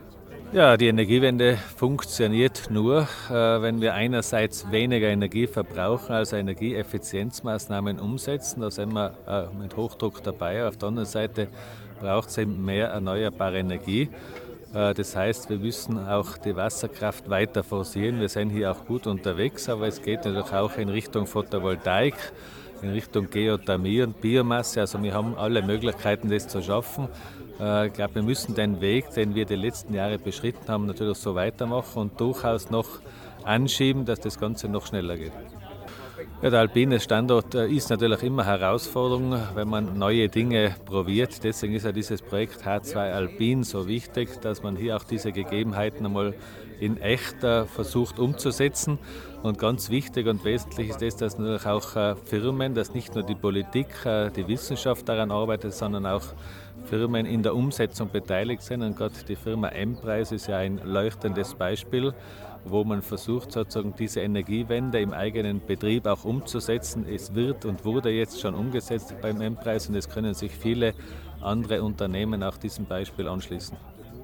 Landeshauptmann Stellvertreter Josef Geisler spricht über die nächsten Schritte im Hinblick auf die Energiewende 2050 und beschreibt die Bedeutung Tirols als alpiner Standort.